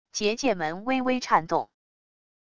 结界门微微颤动wav音频